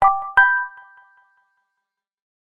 S_Charming_bell.ogg